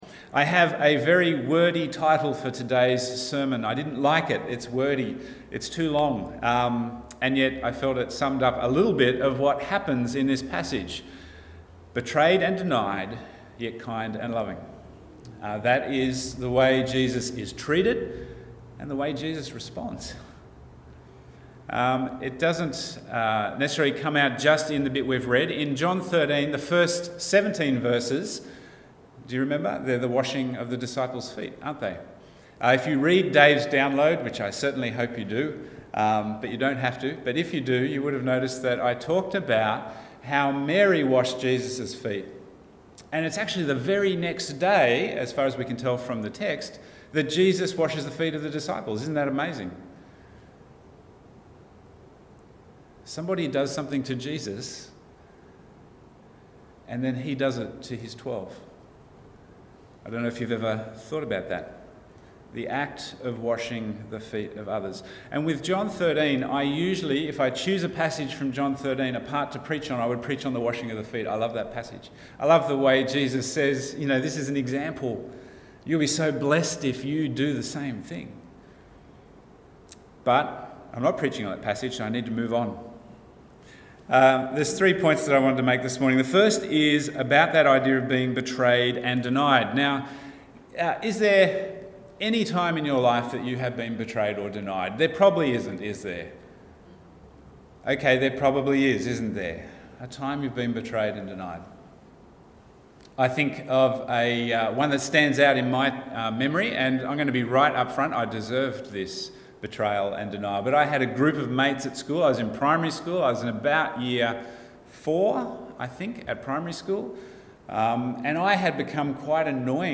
Bible Text: John 13:18-28 | Preacher